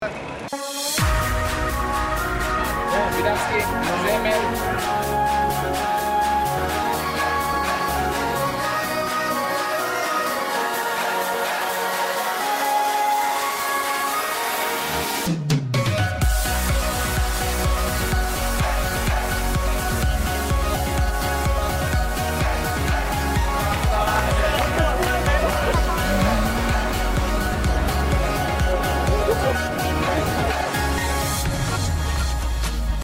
טראק אורגן קורג